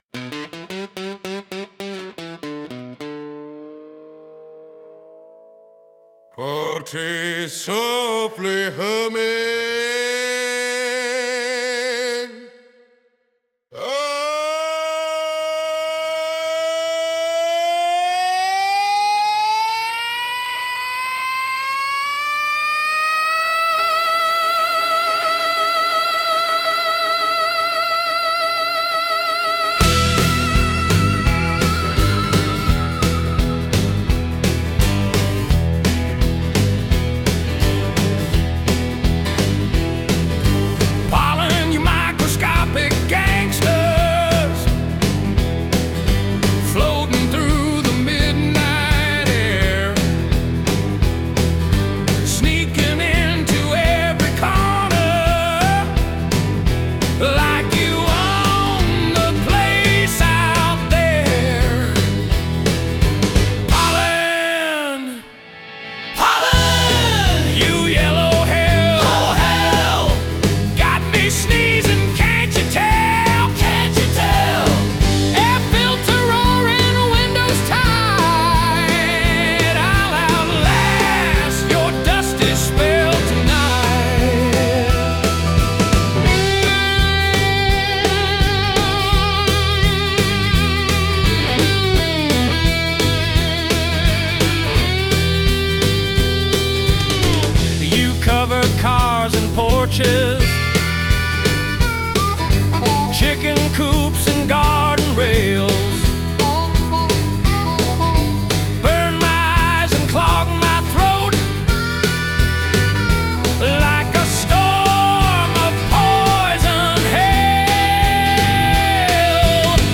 Every spring, the yellow dust descends like an army — covering cars, choking lungs, and turning peaceful nights into sneezing marathons. I got tired of whispering polite prayers to the pollen gods, so I picked up the mic and did what had to be done: turned the rant into a rock‑out gospel blues.
This is Pollen Blues: four and a half minutes of righteous fury with a gospel quartet shouting back at the invisible enemy. Slide guitar wails, the harmonica cries, thunder rolls, and the chorus answers every line like a squad of fellow sufferers. It’s not just a song — it’s an exorcism with a backbeat.